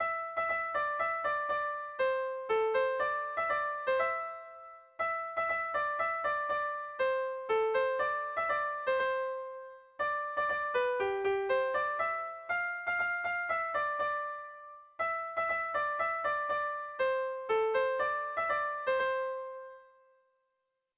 Zortziko ertaina (hg) / Lau puntuko ertaina (ip)
AABA